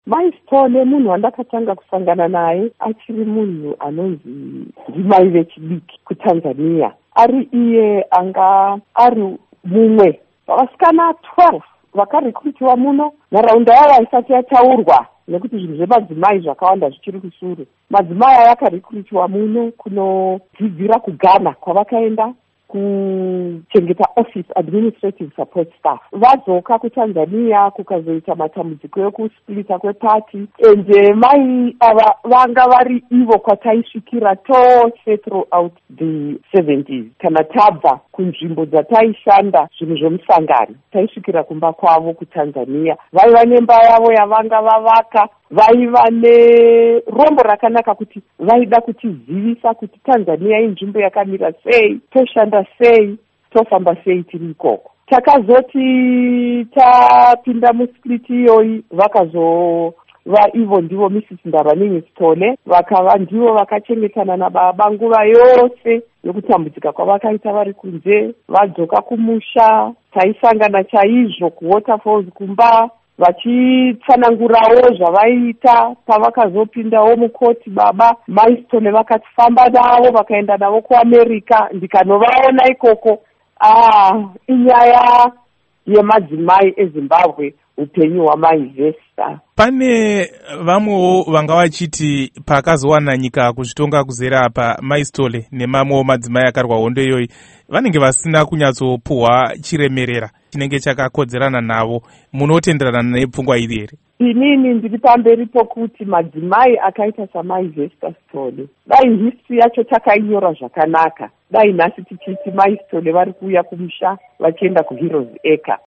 Hurukuro naAmai Sekai Holland